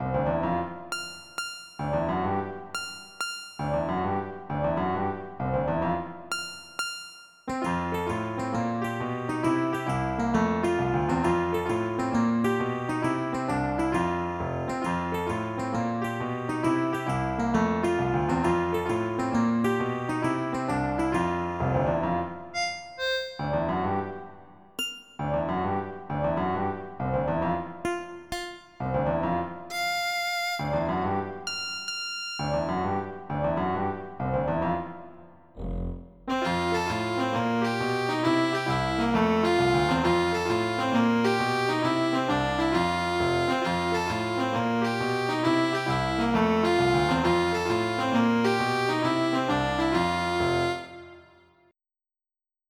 MIDI Music File
General MIDI (type 1)